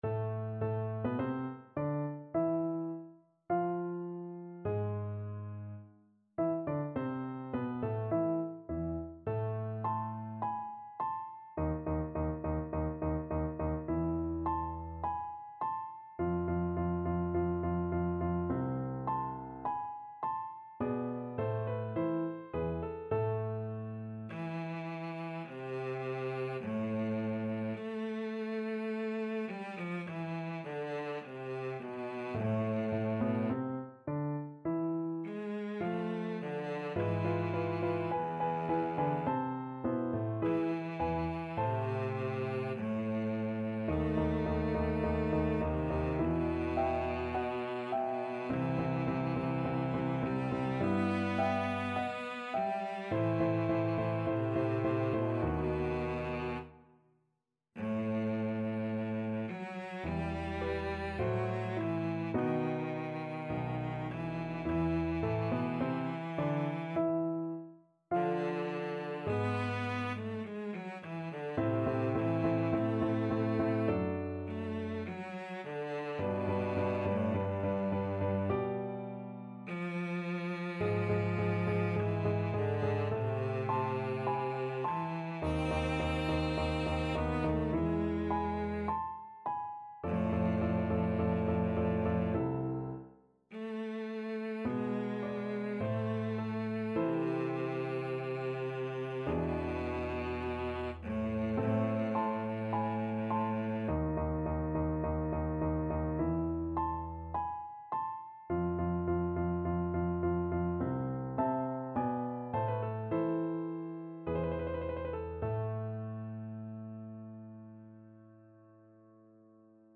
Cello version
4/4 (View more 4/4 Music)
~ = 52 Recit: Andante
Classical (View more Classical Cello Music)